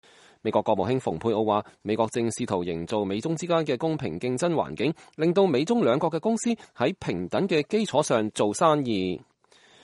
蓬佩奧6月3日在荷蘭海牙與荷蘭外交大臣布洛克舉行聯合記者會。